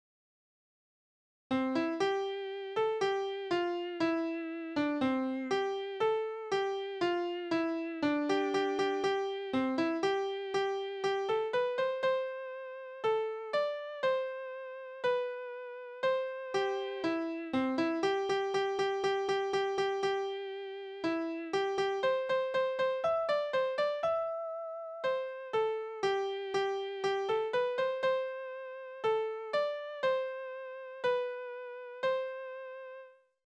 Soldatenlieder:
Tonart: C-Dur
Taktart: 4/4
Tonumfang: große None
Besetzung: vokal